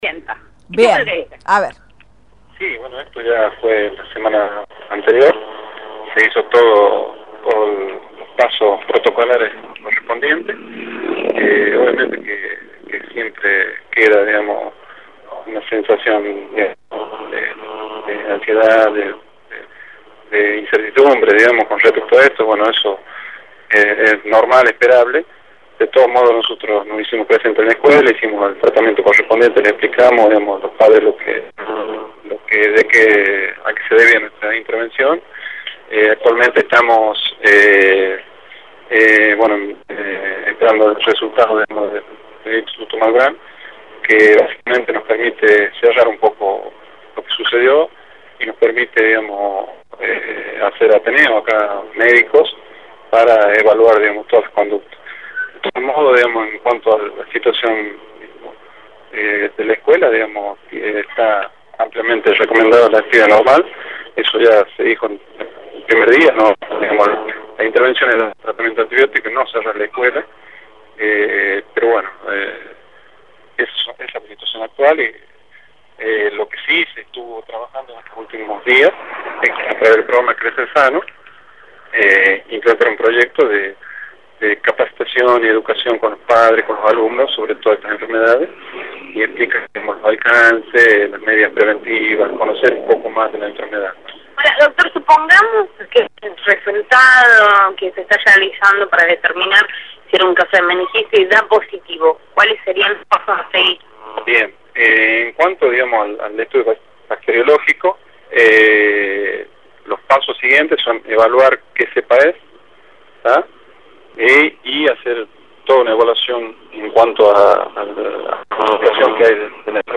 Gustavo Varas, subsecretario por Radio Independiente  Eduardo Bazán, director de Epidemiología por Radio Independiente
En declaraciones a Radio Independiente, el director de Epidemiología, Eduardo Bazán, explicó que la semana pasada se cumplieron los pasos protocolares correspondientes, aunque consideró que es “normal que siempre queda sensación de incertidumbre” en la comunidad escolar.
eduardo-bazc3a1n-director-de-epidemiologc3ada-por-radio-independiente.mp3